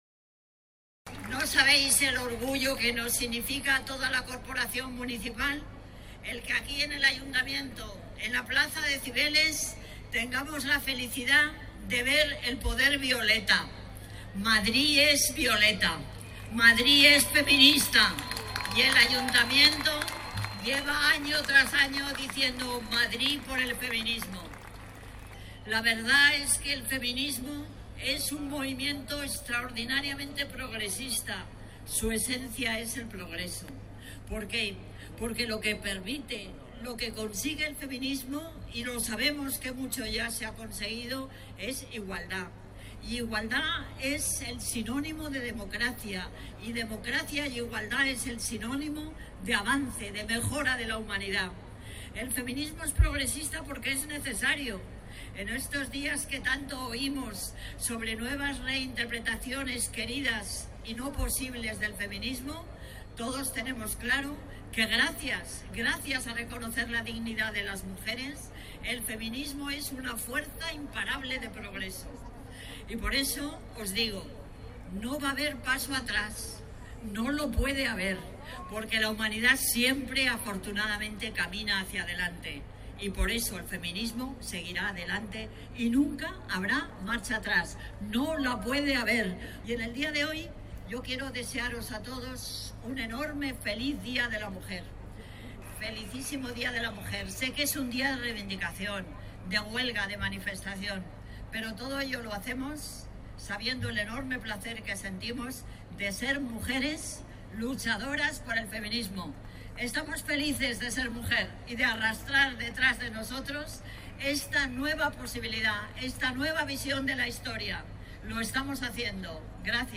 Durante la concentración celebrada en la plaza de Cibeles para conmemorar el Día Internacional de la mujer
Nueva ventana:Palabras de Manuela Carmena durante la concentración de la plaza de Cibeles